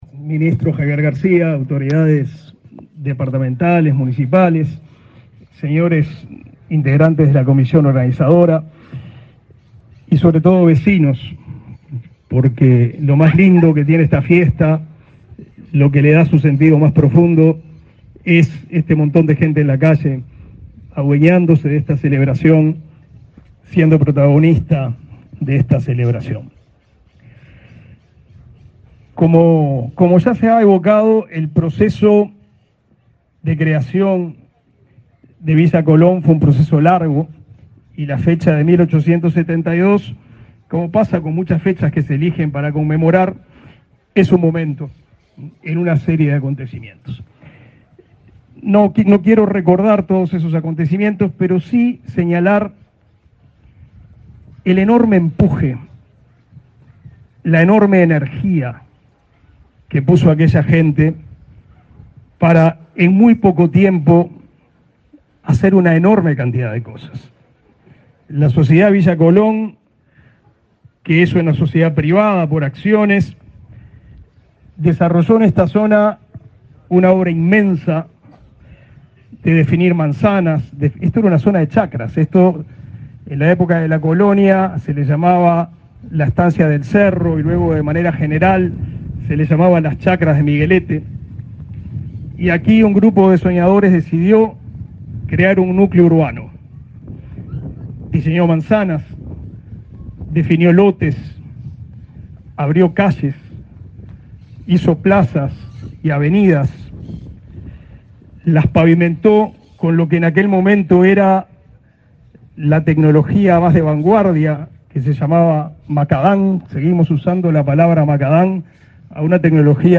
Palabras del ministro de Educación y Cultura, Pablo da Silveira, en aniversario de Villa Colón
Palabras del ministro de Educación y Cultura, Pablo da Silveira, en aniversario de Villa Colón 16/10/2022 Compartir Facebook X Copiar enlace WhatsApp LinkedIn Con la presencia del presidente de la República, Luis Lacalle Pou, se realizó, este 16 de octubre, la celebración de los 150 años del barrio Villa Colón. En la oportunidad, se expresó el ministro de Educación y Cultura.